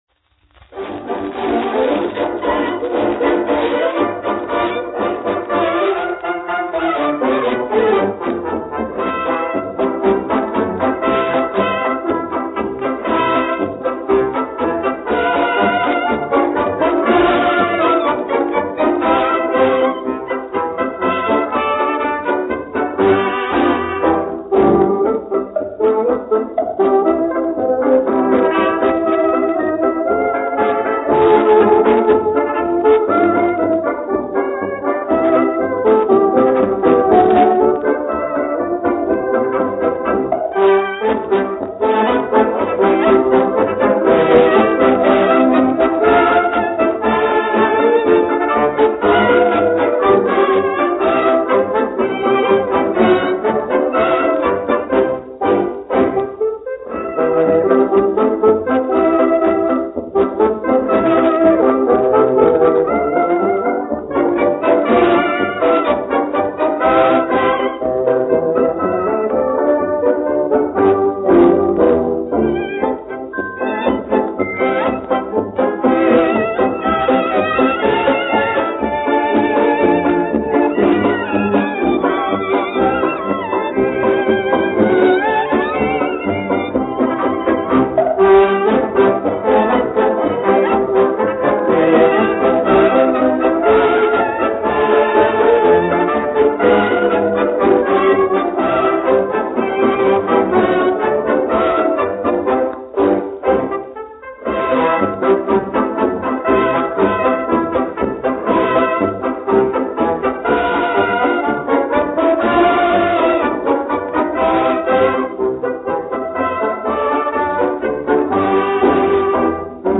Грампластинка 1929 года
Да, это фокстрот.